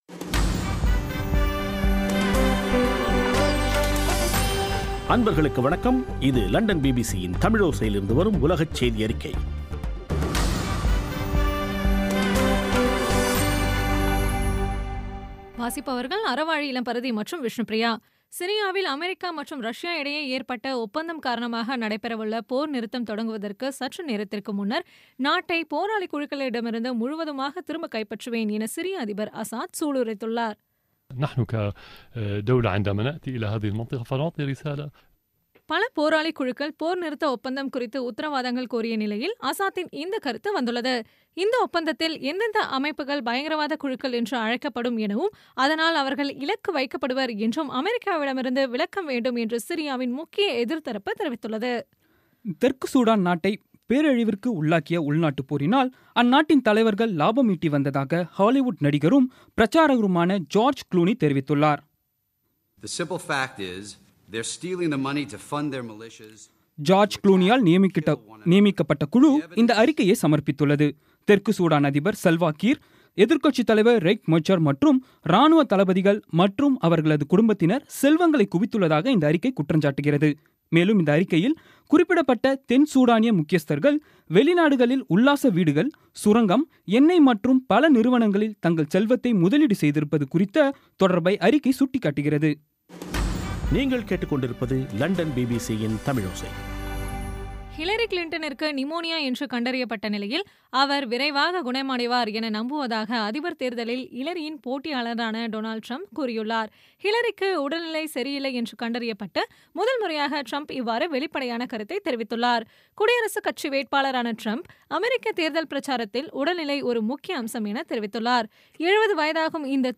இன்றைய (ஆகஸ்ட் 12ம் தேதி ) பிபிசி தமிழோசை செய்தியறிக்கை